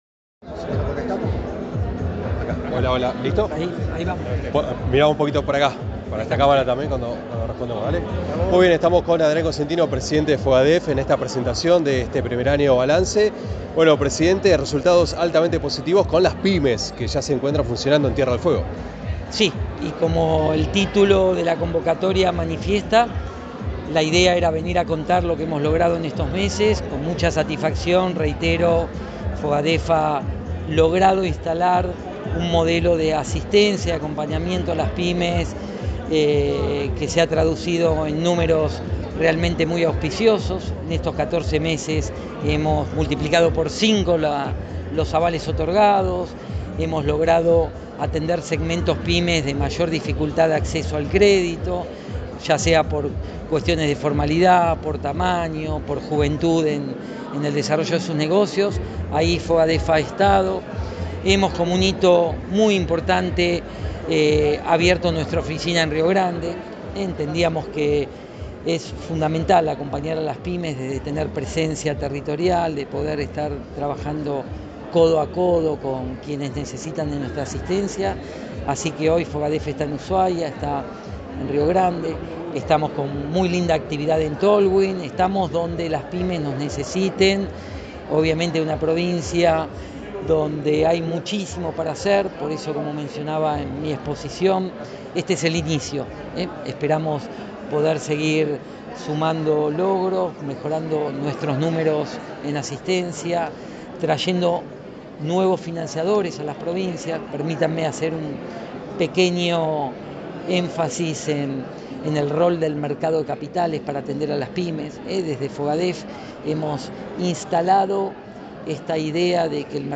Audio Adrián Cosentino Presidente de FOGADEF